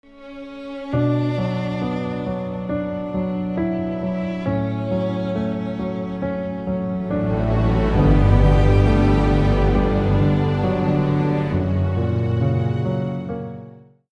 בעיקרון אני שריך כינורות שנשמעים כמו הכינורות בקטע הזה untitled.mp3